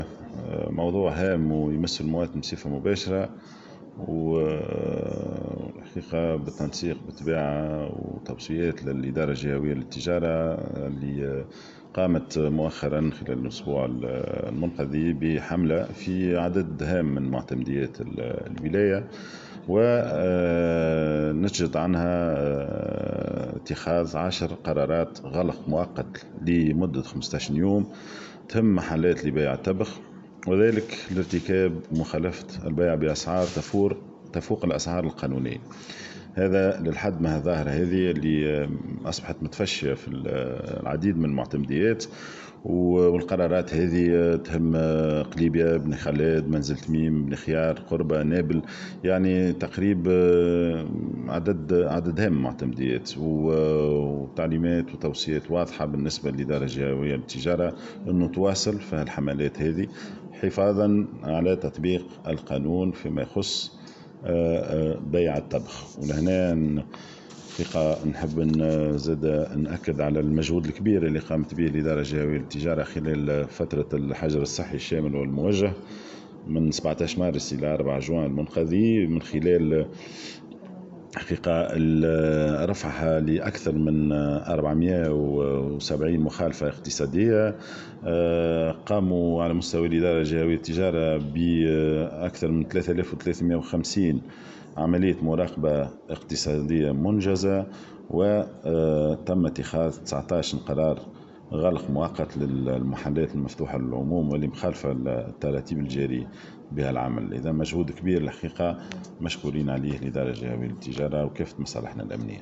أفاد والي نابل محمد رضا مليكة في تصريح لمراسلة "الجوهرة أف أم" اليوم الأحد أنه تقرّر غلق محلات بيع معتمدة لبيع التبغ بسبب بيعها السجائر بأسعار مخالفة للقانون.